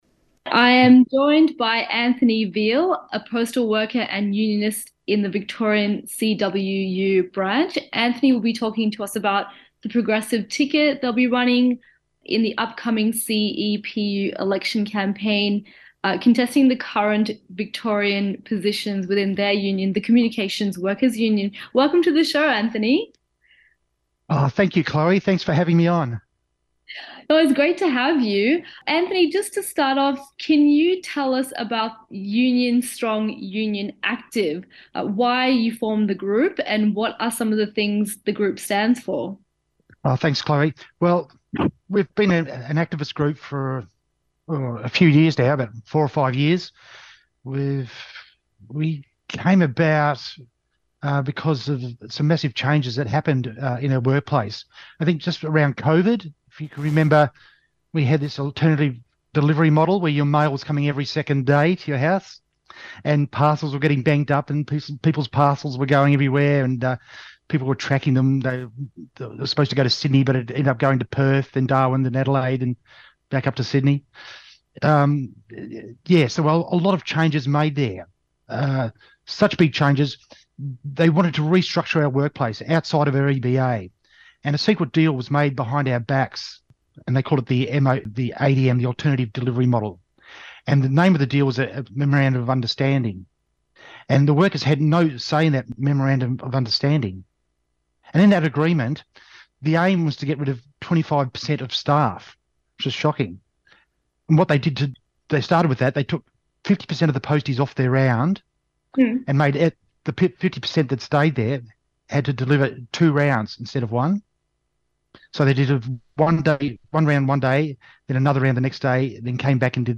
Interviews and Discussions